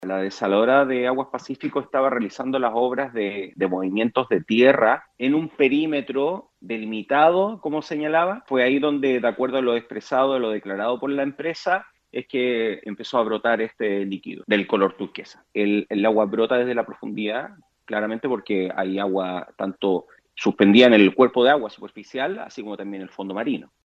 El capitán de Puerto, Ricardo Cartes, aseguró que el material “posiblemente” contaminante, era un líquido de color azul turquesa que salió a flote producto de las excavaciones submarinas realizadas por la desalinizadora Aguas del Pacífico.